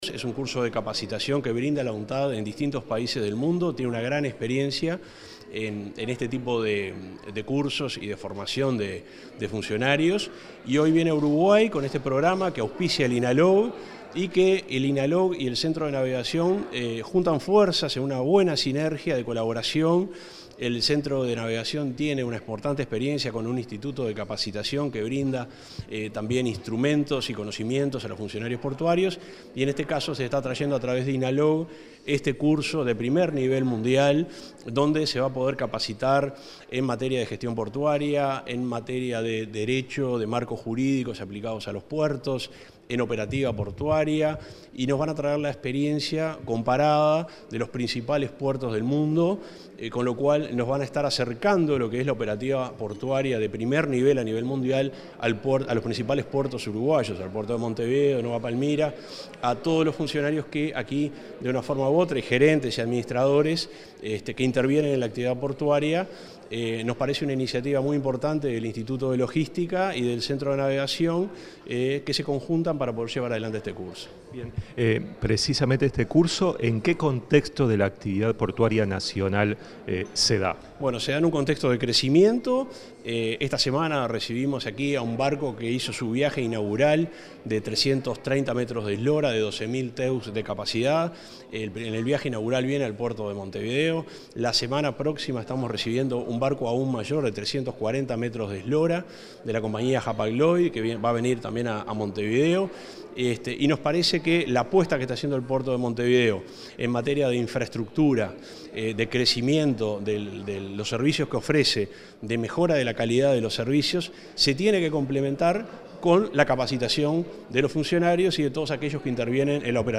Entrevista al subsecretario de Transporte, Juan José Olaizola